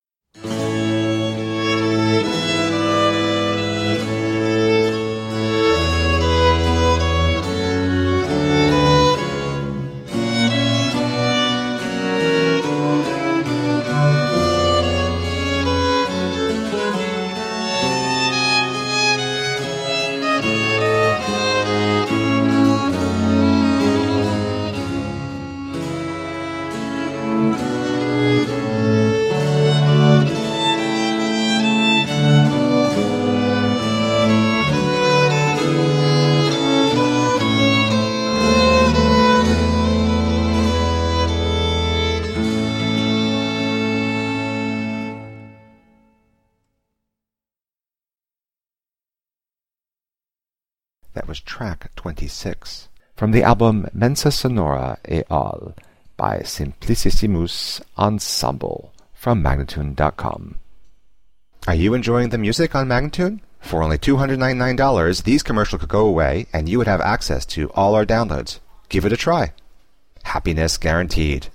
17th and 18th century classical music on period instruments
Classical, Instrumental Classical, Classical Period, Baroque